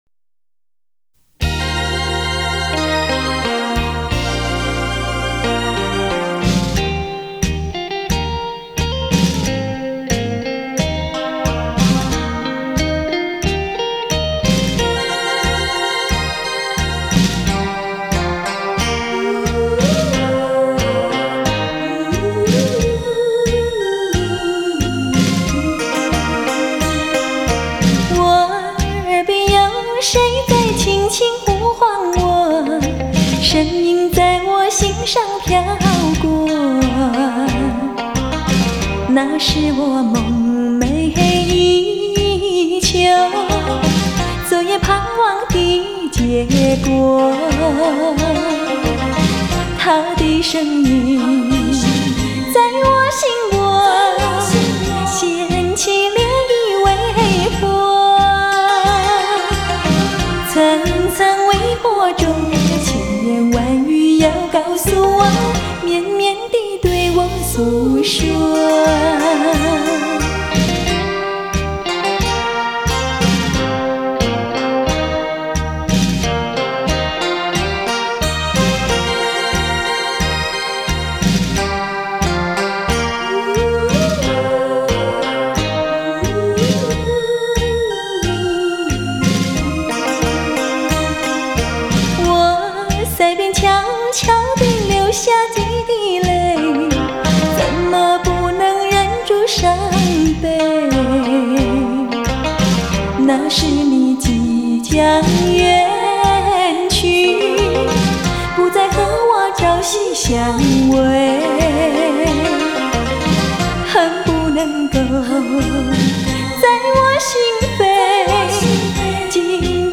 轻音乐+真柔情